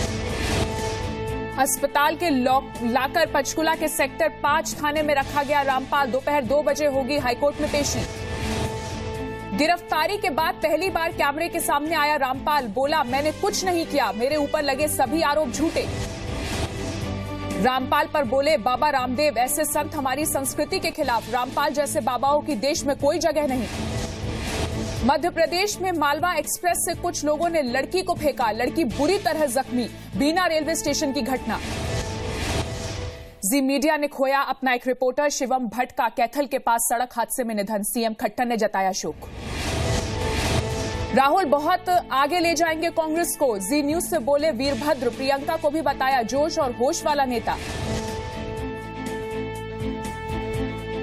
Headlines of the day